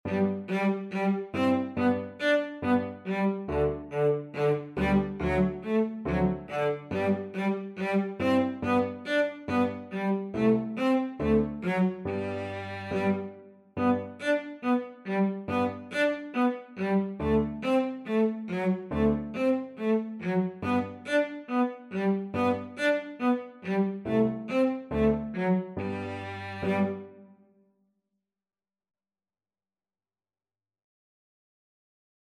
4/4 (View more 4/4 Music)
Fast = c. 140